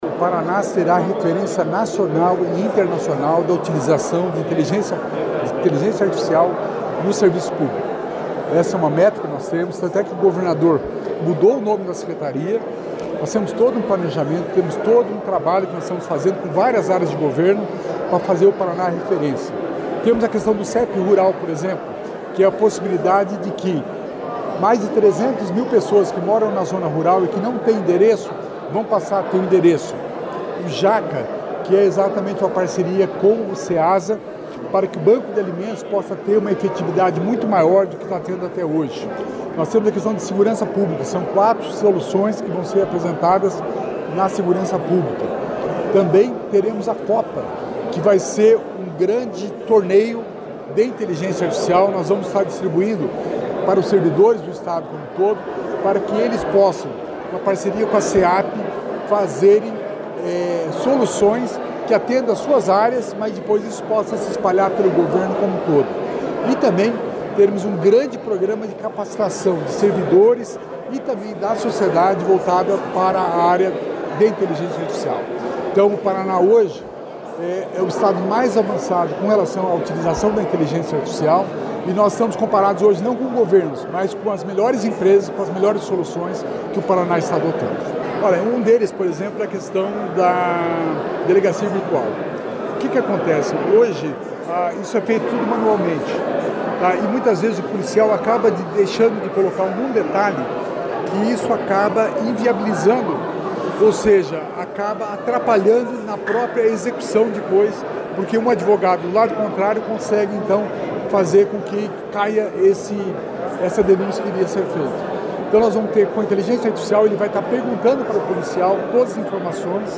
Sonora do secretário da Inovação e Inteligência Artificial, Alex Canziani, sobre o lançamento do pacote de soluções de Inteligência Artificial